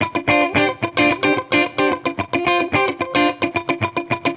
Index of /musicradar/sampled-funk-soul-samples/110bpm/Guitar
SSF_TeleGuitarProc1_110D.wav